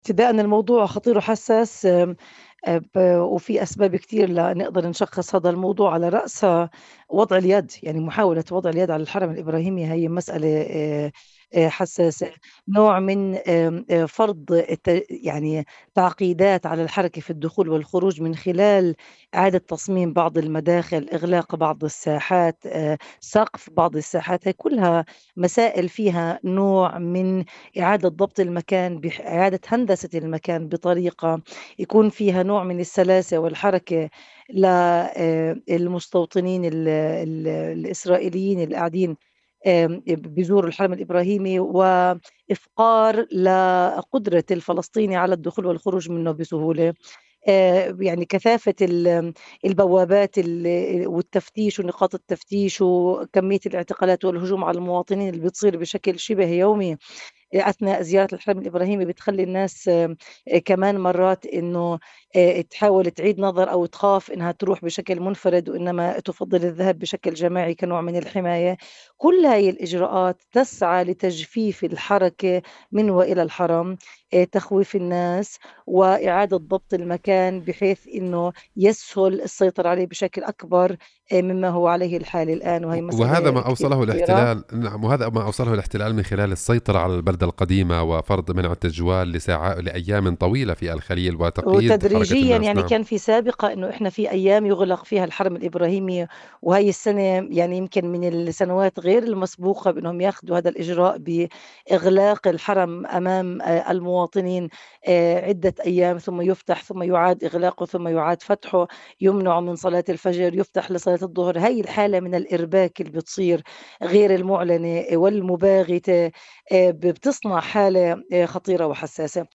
نائبة رئيس بلدية الخليل لإذاعة “قناة القدس”: الاحتلال ينفذ خطوات غير مسبوقة لتغيير الوضع القائم في الحرم الإبراهيمي